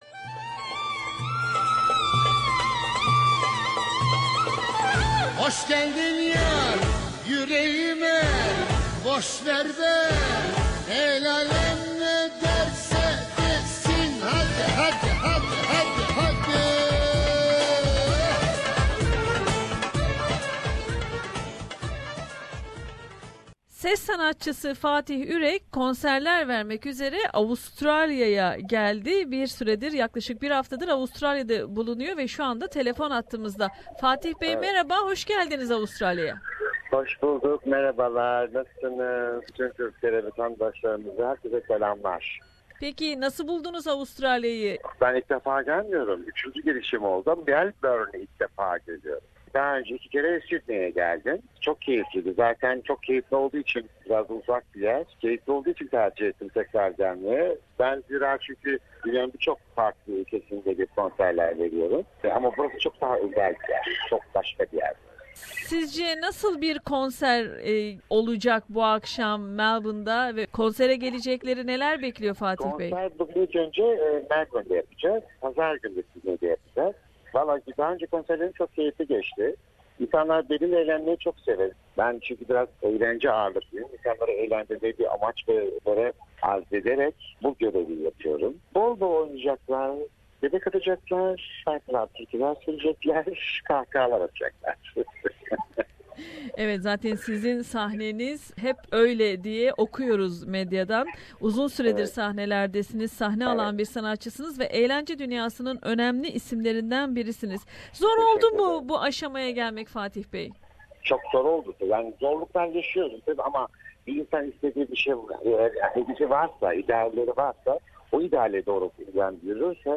Melbourne ve Sydney'de konserler vermek üzere Avustralya'ya gelen ses sanatçısı Fatih Ürek ile bir söyleşi yaptık.